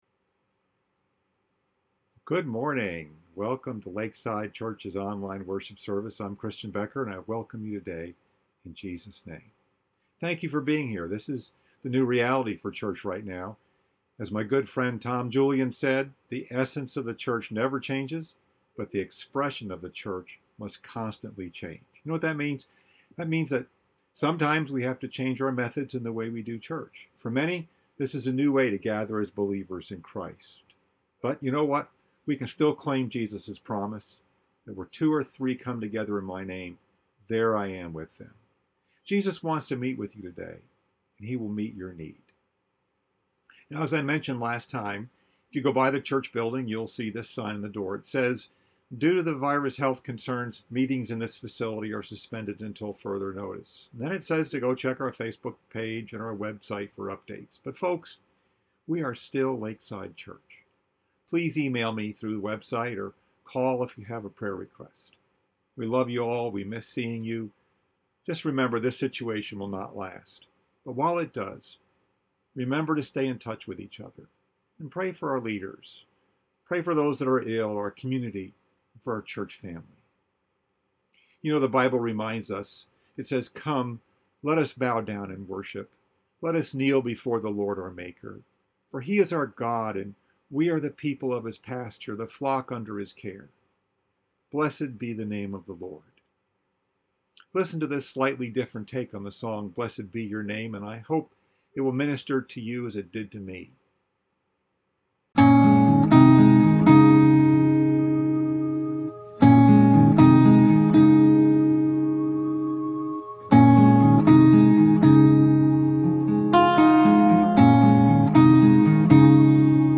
Message: “What’s the Next Step?” Scripture: John 21